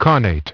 Transcription and pronunciation of the word "connate" in British and American variants.